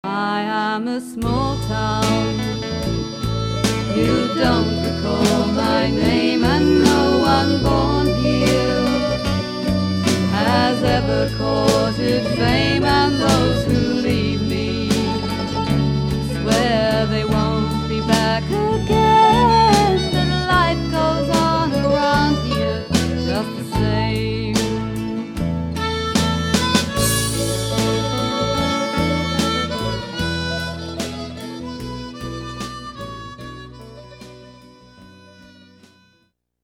Little Pink Studio, New Plymouth